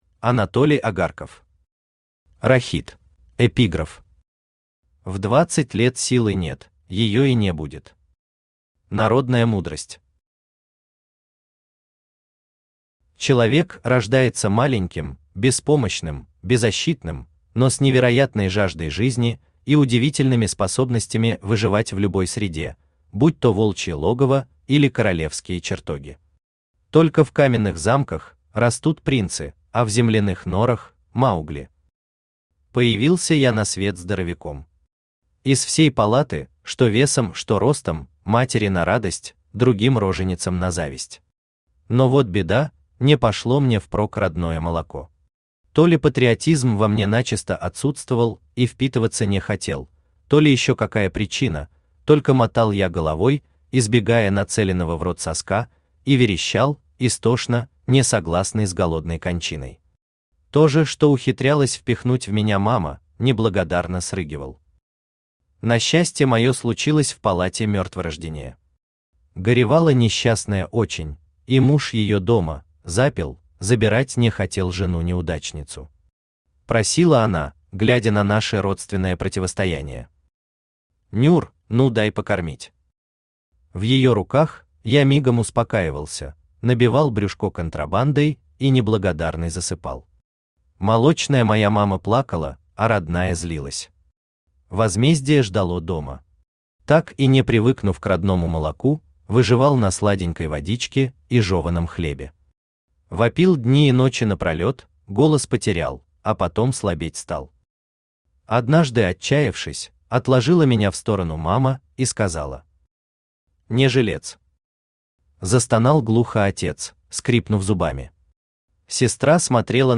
Аудиокнига Рахит | Библиотека аудиокниг
Aудиокнига Рахит Автор Анатолий Агарков Читает аудиокнигу Авточтец ЛитРес.